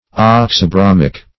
Search Result for " oxybromic" : The Collaborative International Dictionary of English v.0.48: Oxybromic \Ox`y*bro"mic\, a. [Oxy (a) + bromic.]